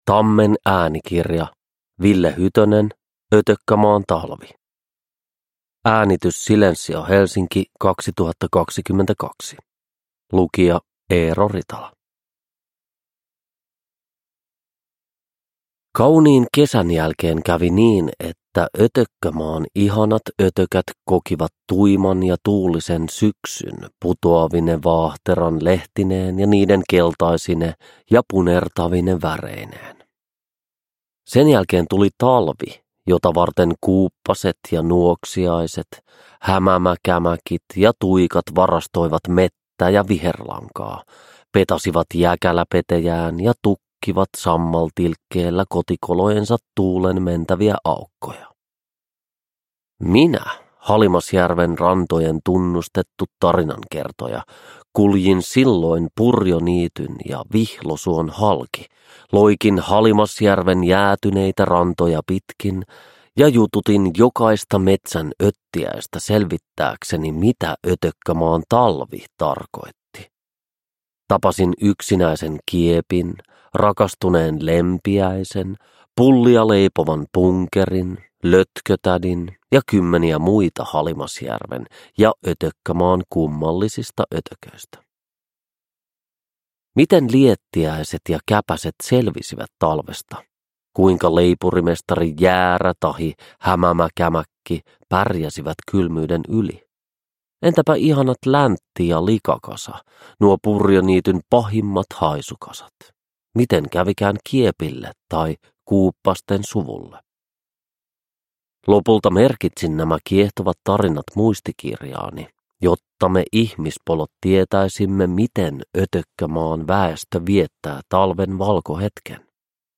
Uppläsare: Eero Ritala